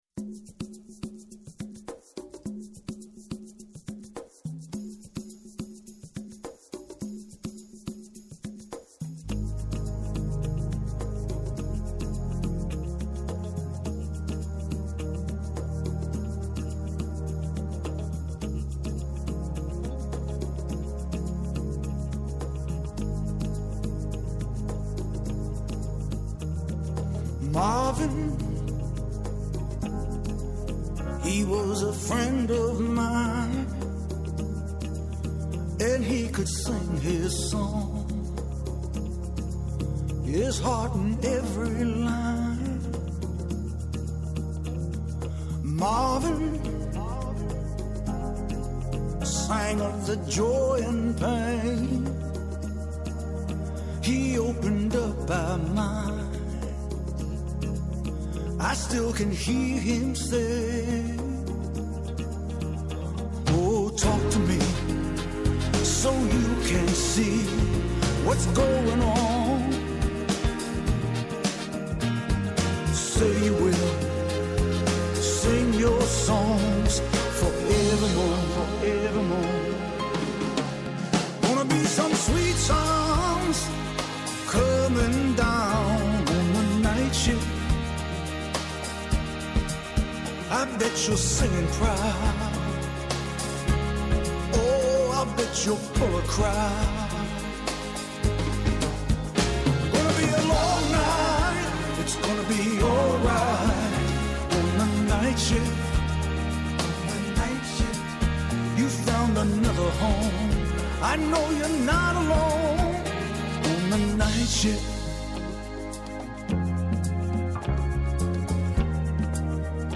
Και Ναι μεν η ματιά μας στρέφεται στην εγχώρια επικαιρότητα, Αλλά επειδή ο κόσμος “ο μικρός ο μέγας” -όπως το διατύπωσε ο ποιητής- είναι συχνά ο περίγυρός μας, θέλουμε να μαθαίνουμε και να εντρυφούμε στα νέα του παγκόσμιου χωριού. Έγκριτοι επιστήμονες, καθηγητές και αναλυτές μοιράζονται μαζί μας τις αναλύσεις τους και τις γνώσεις τους.